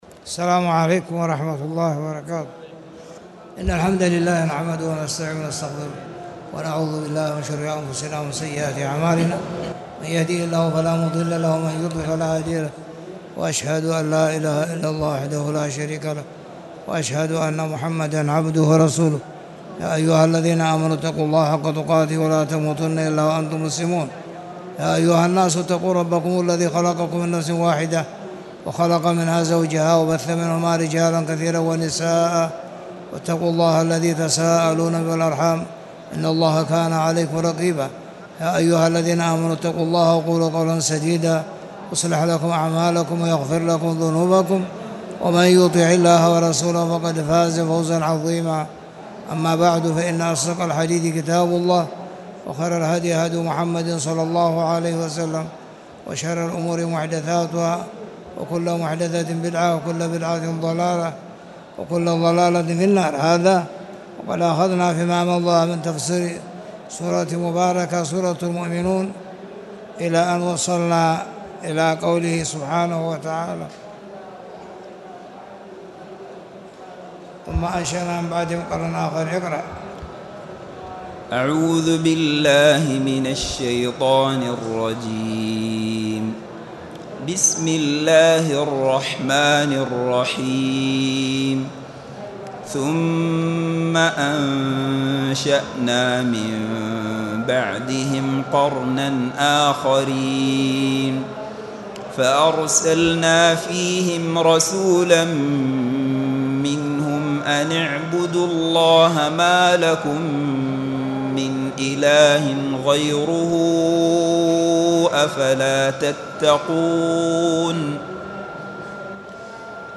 تاريخ النشر ٥ رمضان ١٤٣٨ هـ المكان: المسجد الحرام الشيخ